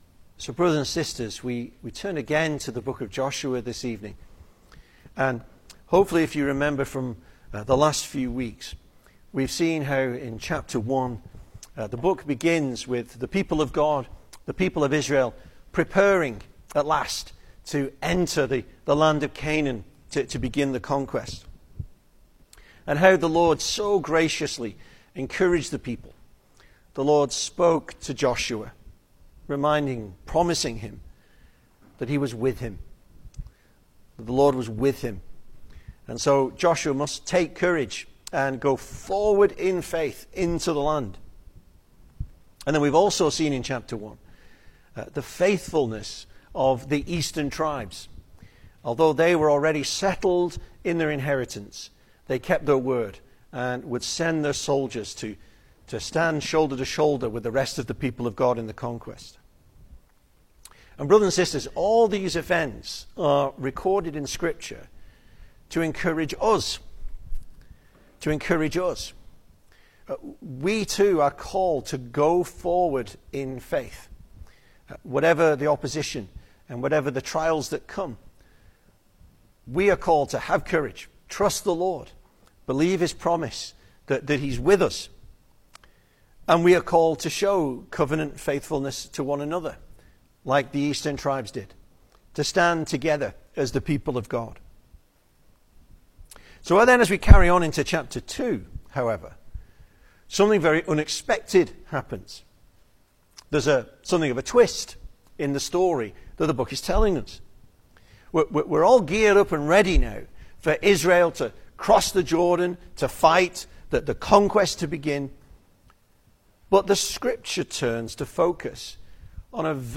2025 Service Type: Sunday Evening Speaker